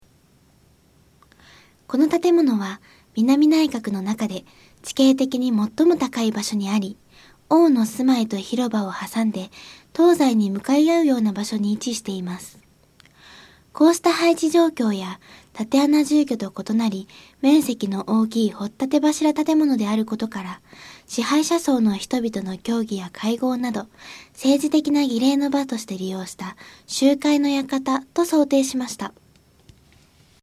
こうした配置状況や、竪穴住居と異なり面積の大きい掘立柱建物であることから、支配者層の人々の協議や会合など政治的な儀礼の場として利用した「集会の館」と想定しました。 音声ガイド 前のページ 次のページ ケータイガイドトップへ (C)YOSHINOGARI HISTORICAL PARK